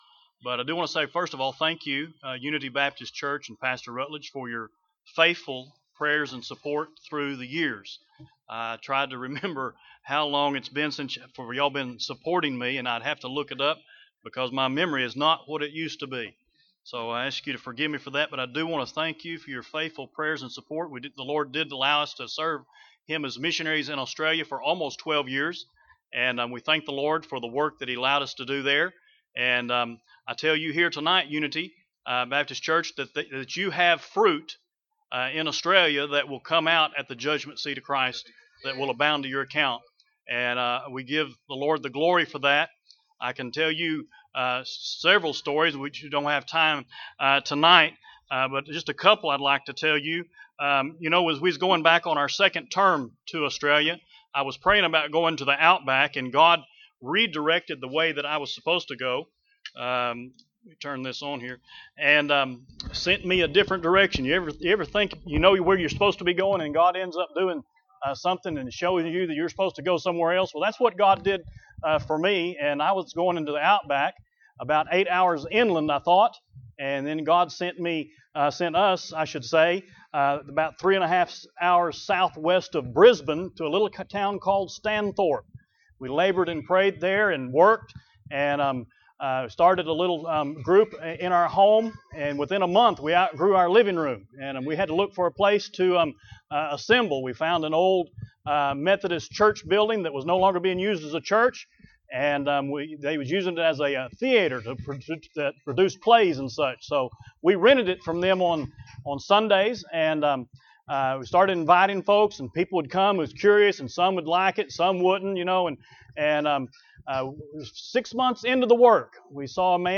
4 Service Type: Mission Conference Bible Text